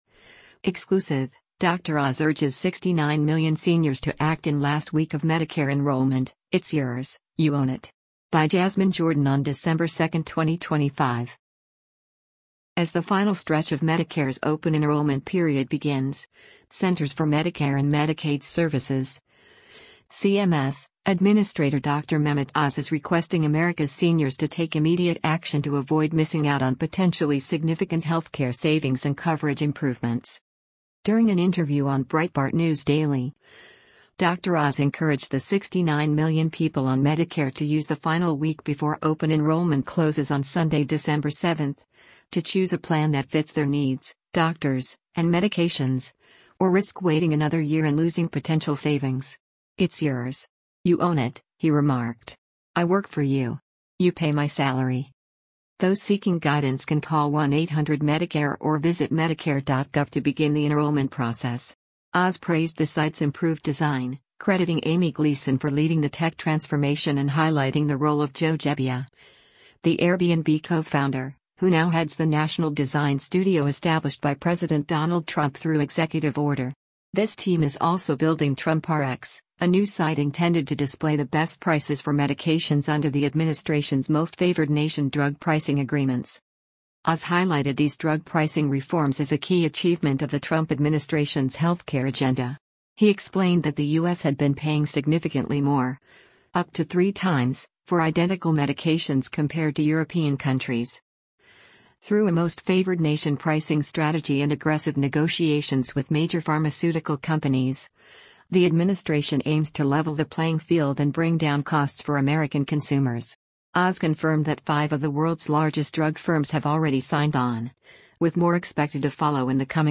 During an interview on Breitbart News Daily, Dr. Oz encouraged the 69 million people on Medicare to use the final week before open enrollment closes on Sunday, December 7, to choose a plan that fits their needs, doctors, and medications — or risk waiting another year and losing potential savings.